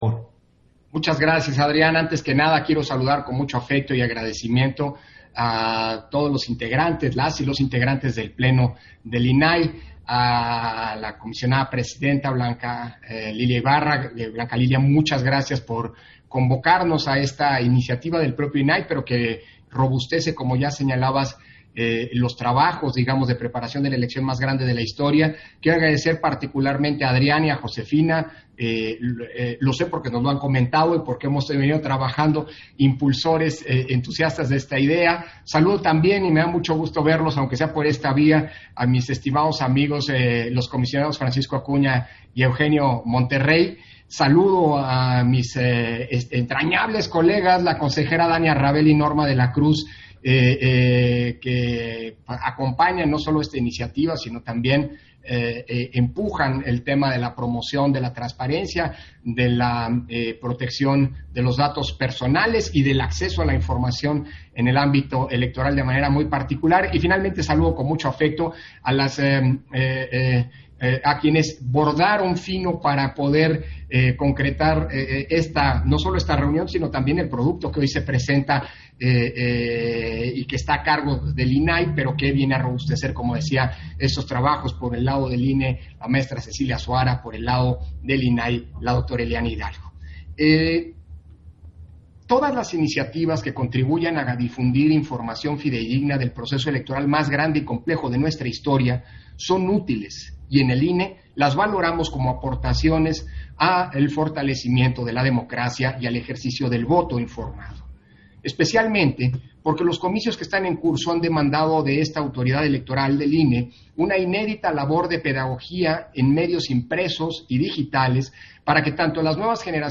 180521_AUDIO_INTERVENCIÓN-CONSEJERO-PDTE.-CÓRDOVA-PRESENTACIÓN-DEL-MICROSITIO - Central Electoral